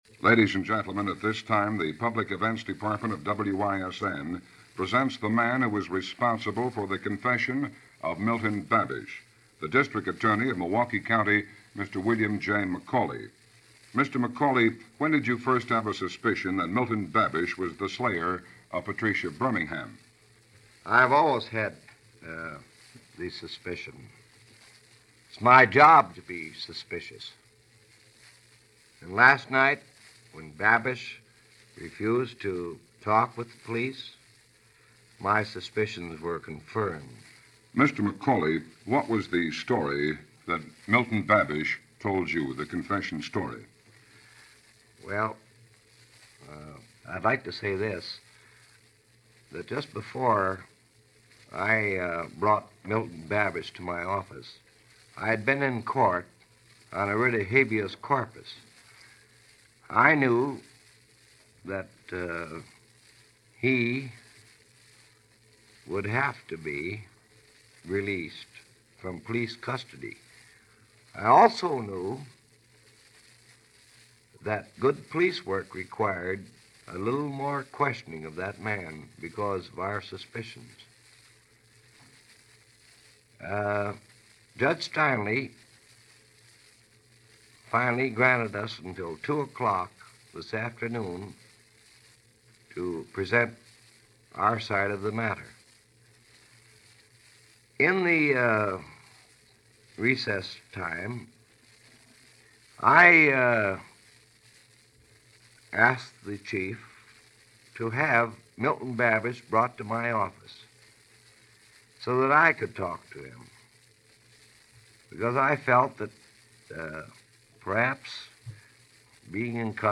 You just never know – here is an interview with District Attorney Mc Auley who got the confession based on “a hunch” as it was broadcast March 26, 1949 over WISN, Milwaukee.